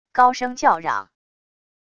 高声叫嚷wav音频